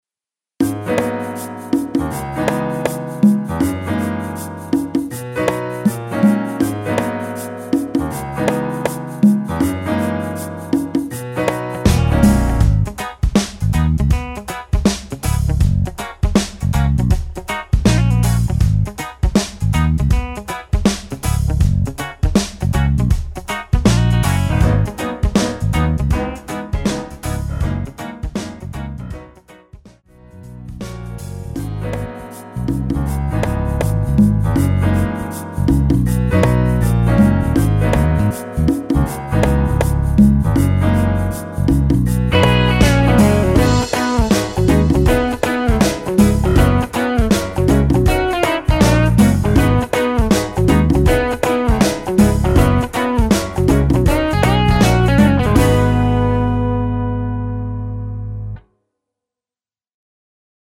엔딩이 페이드 아웃이라 라이브 하시기 좋게 엔딩을 만들어 놓았습니다.(미리듣기 참조)
Abm
앞부분30초, 뒷부분30초씩 편집해서 올려 드리고 있습니다.